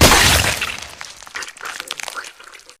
gore6.ogg